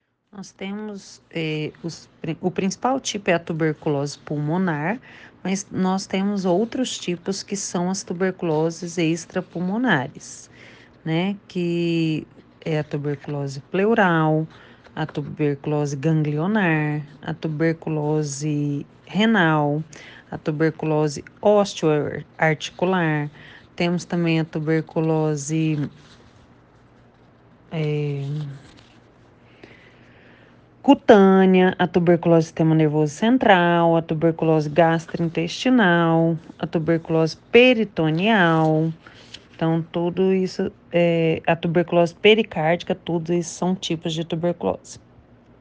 Especialista explica como se prevenir e tratar da doença que é considerada um problema de saúde pública